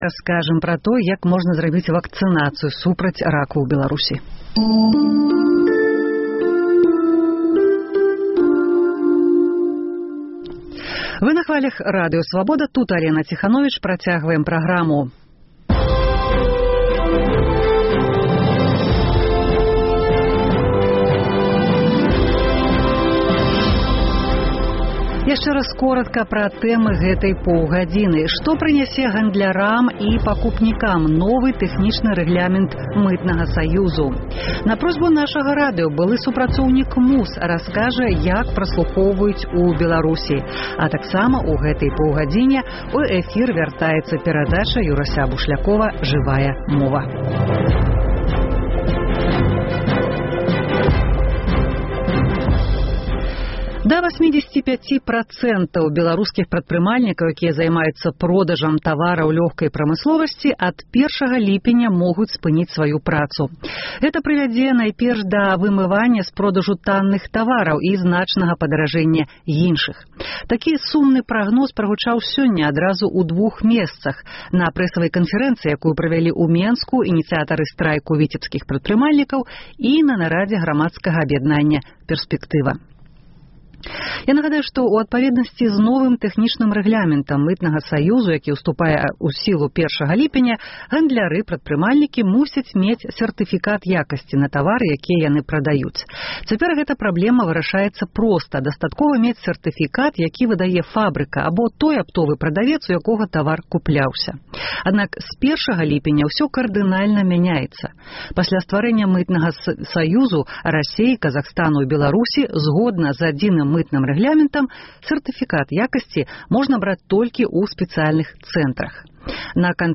Рэпартаж з вёскі Мардзьвін Петрыкаўскага раёну. Беларускія аспэкты амэрыканскага скандалу з сачэньнем і праслухоўваньнем. Дзе і як ў Беларусі можна зрабіць вакцынацыю супраць раку жаночых органаў?